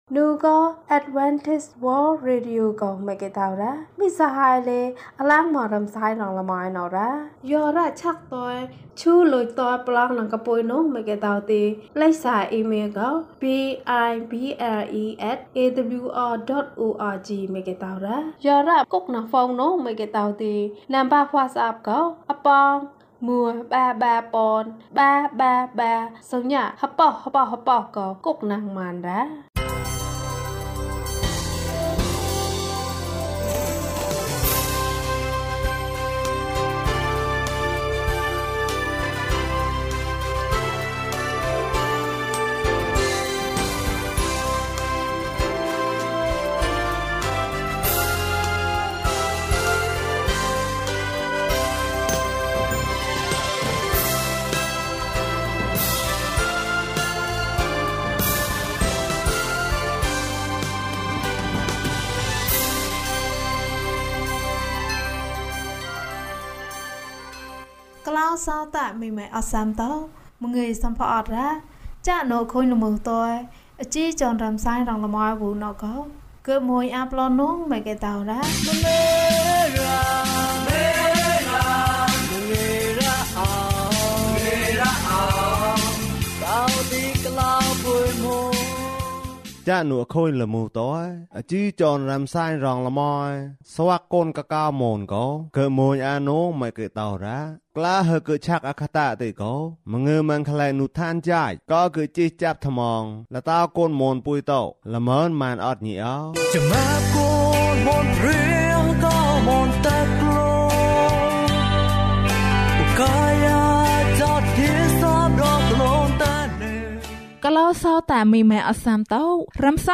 ငါ့အသက်ကို ပို့ဆောင်ပါ။၀၂ ကျန်းမာခြင်းအကြောင်းအရာ။ ဓမ္မသီချင်း။ တရားဒေသနာ။